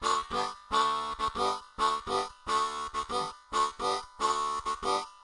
口琴练习剪辑 " 口琴快速弯曲静音03
Tag: 口琴 节奏 重点 G